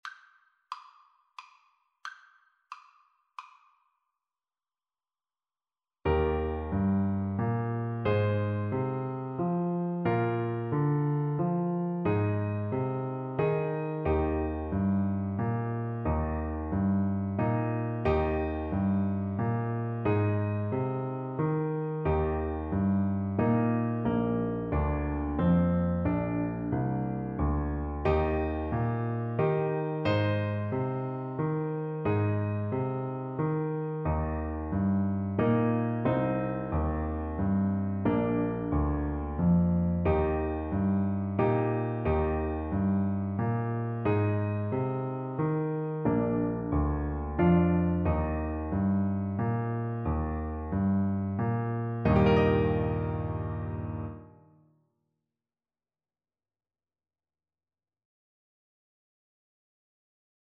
French Horn
3/4 (View more 3/4 Music)
Eb major (Sounding Pitch) Bb major (French Horn in F) (View more Eb major Music for French Horn )
= 120 Slow one in a bar
Traditional (View more Traditional French Horn Music)